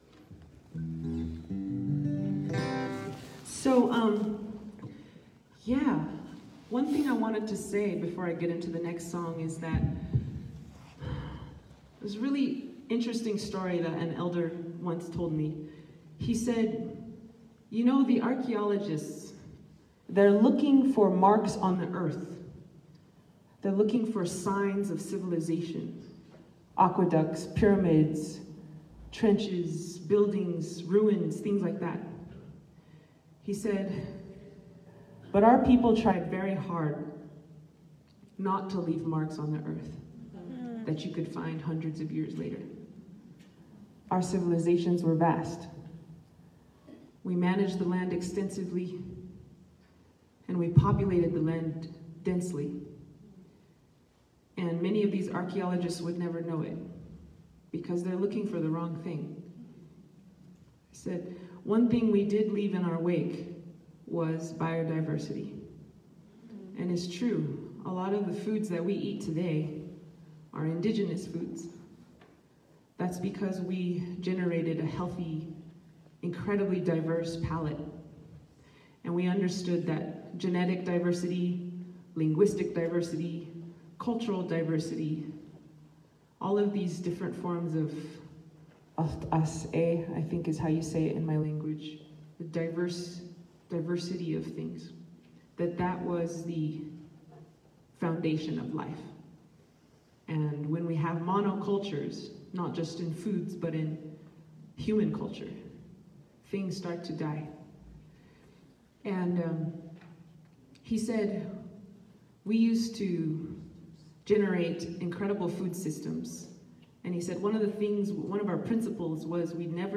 lifeblood: bootlegs: 2020-02-06: the alhambra theater in the scottish rite temple - santa fe, new mexico ("it takes a village" honor the earth benefit concert)
(captured from a facebook live stream)
04. talking with the crowd